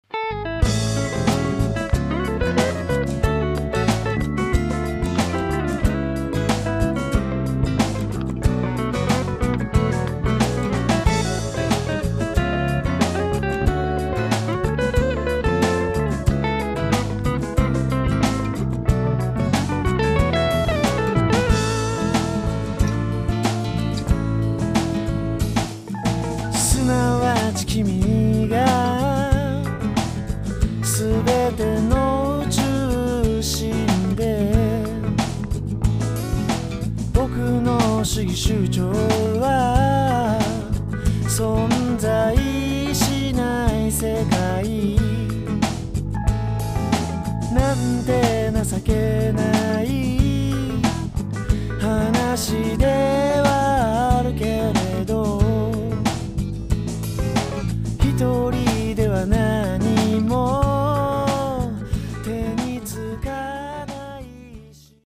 生のバンドでやった方が活き活きとしてくるような感じもあります。